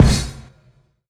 break_kick_9.wav